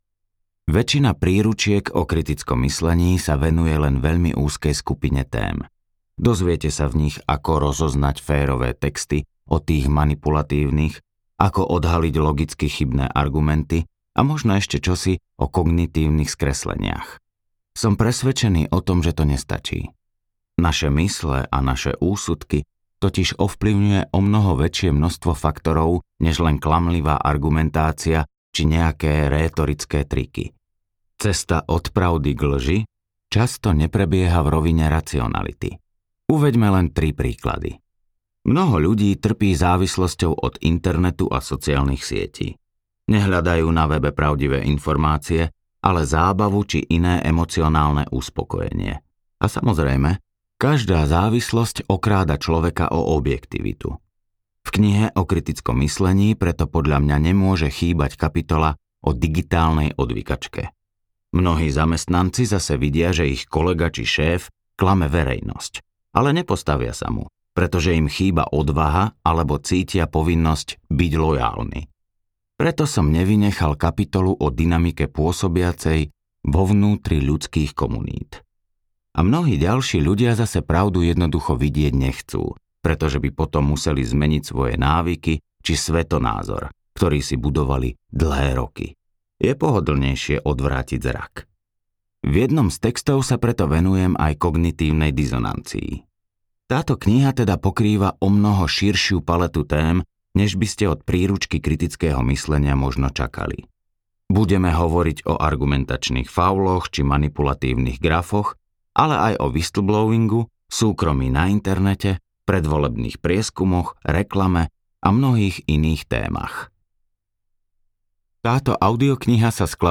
Sila rozumu v bláznivej dobe audiokniha
Ukázka z knihy